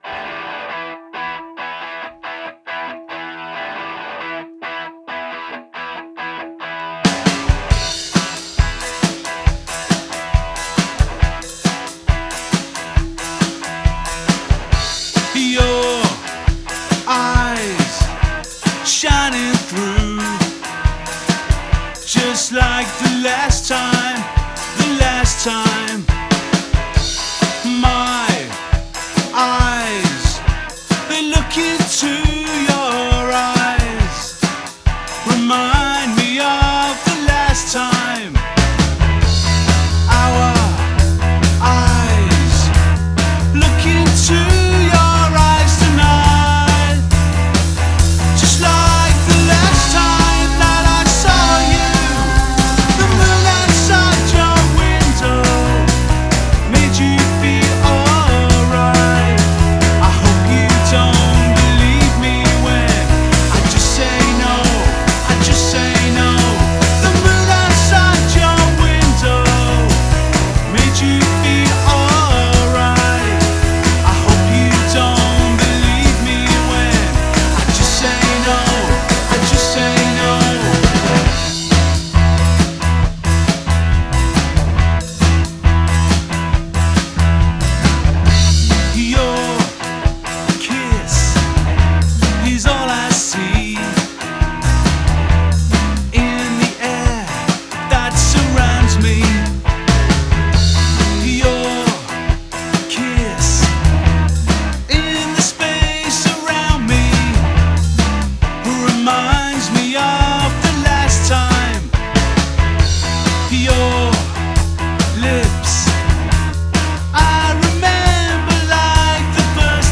• Genre: Pop / Rock
Gesang
Bassgitarre
Gitarre
Schlagzeug